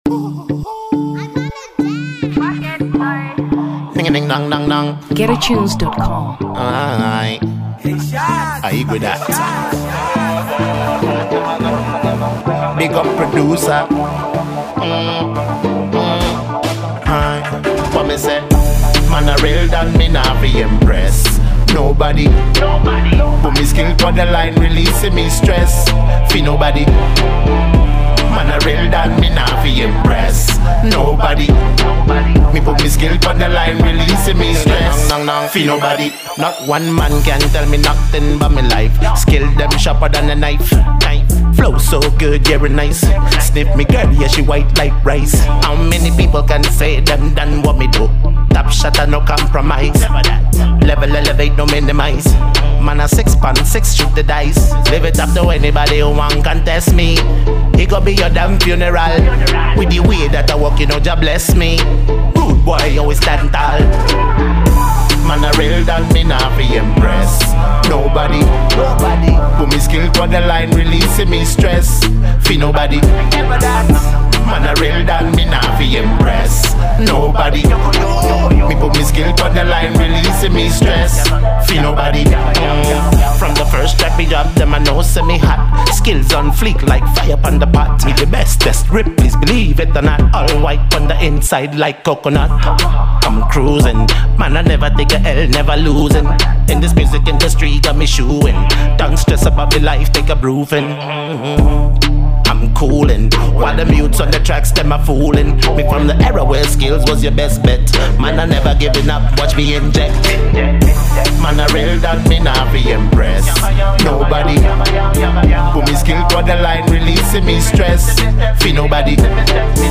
Dancehall 2023 Malawi